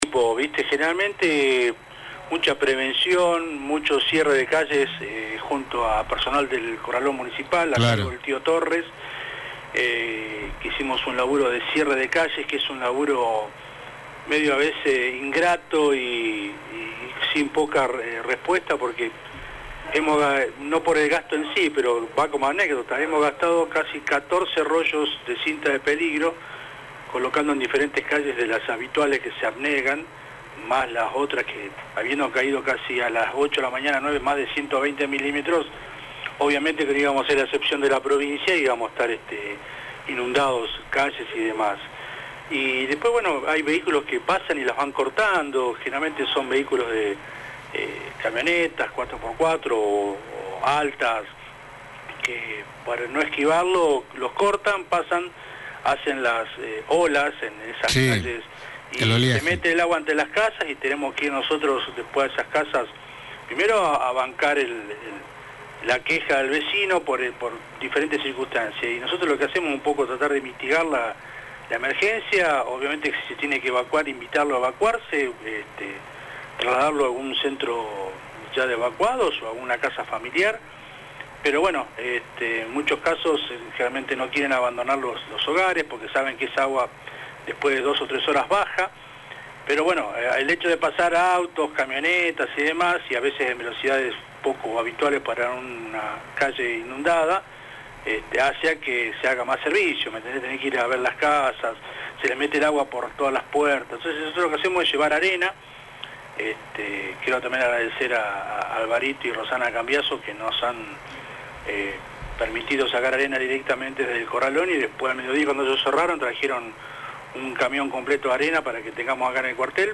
En comunicación telefónicas con FM San Gabriel